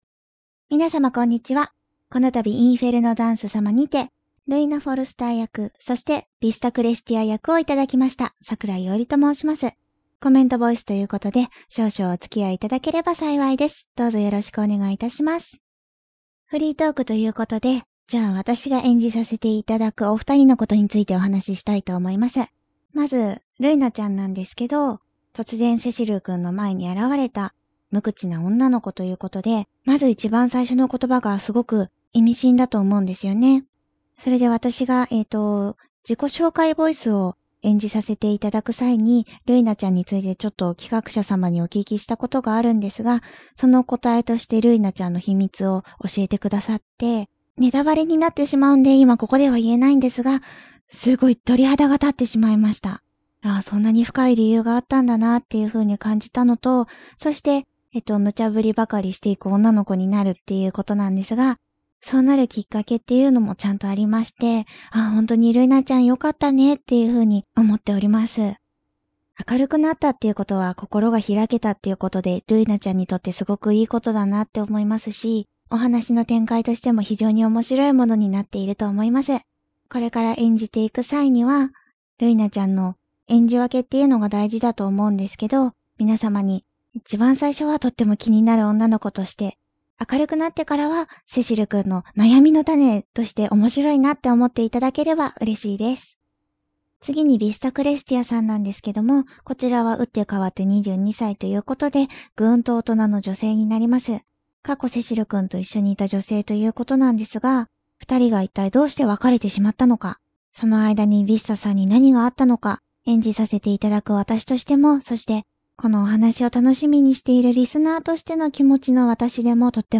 コメントボイス"